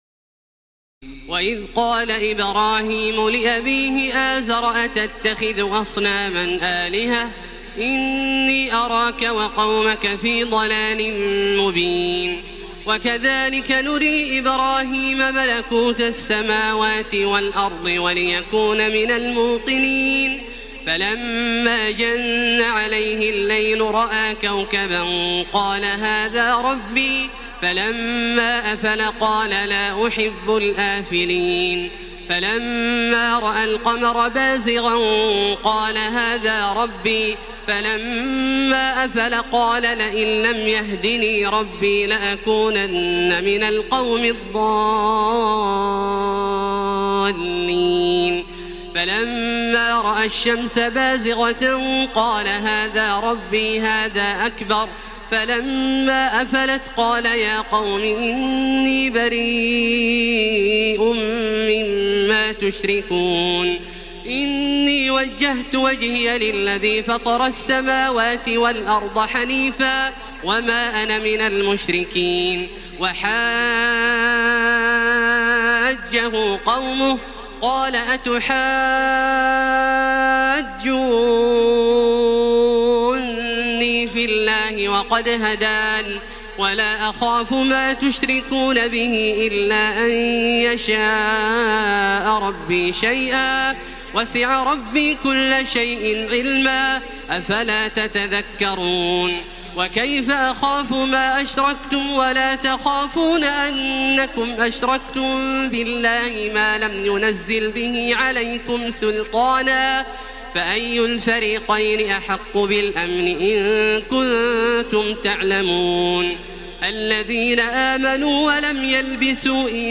تراويح الليلة السابعة رمضان 1433هـ من سورة الأنعام (74-150) Taraweeh 7 st night Ramadan 1433H from Surah Al-An’aam > تراويح الحرم المكي عام 1433 🕋 > التراويح - تلاوات الحرمين